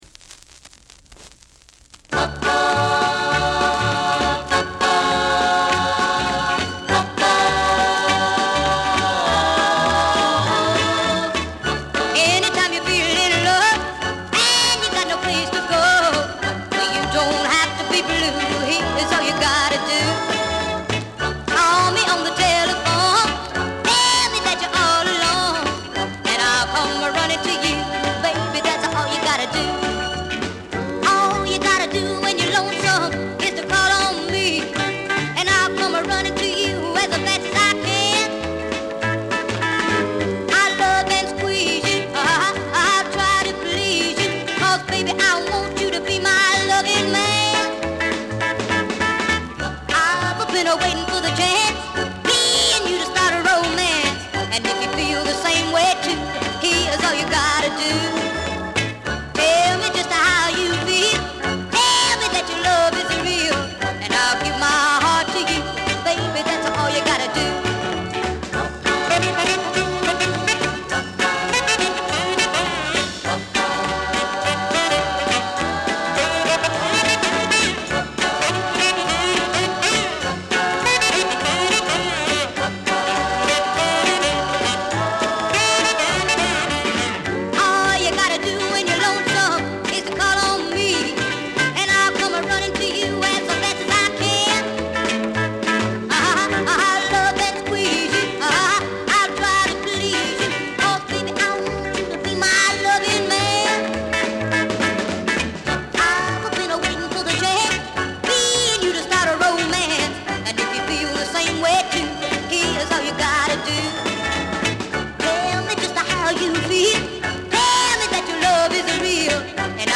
45 RPM Vinyl Record